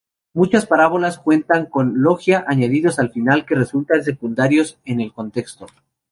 /konˈteɡsto/